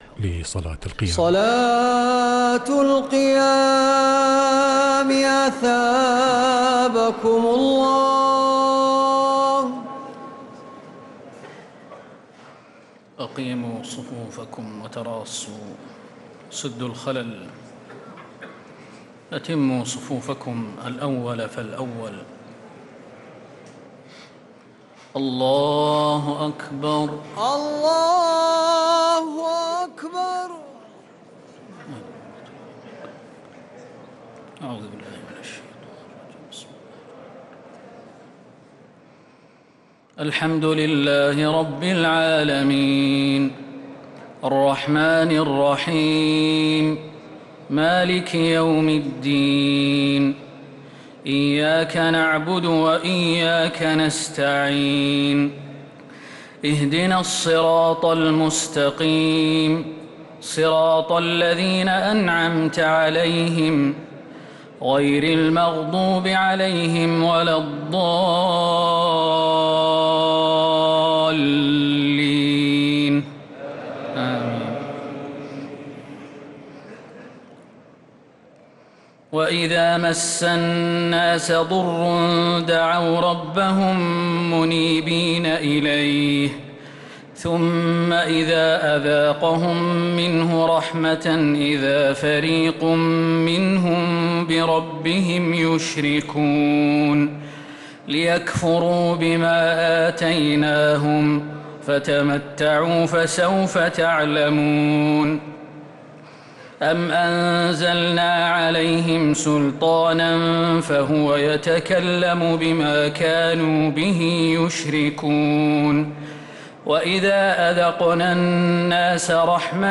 تراويح ليلة 24 رمضان 1446هـ من سورة الروم (33-60) إلى سورة السجدة كاملة | taraweeh 24th night Ramadan 1446H surah Ar-Rum to as-Sajdah > تراويح الحرم النبوي عام 1446 🕌 > التراويح - تلاوات الحرمين